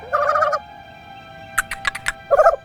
File:Small Notzilla roar.ogg
Small_Notzilla_roar.ogg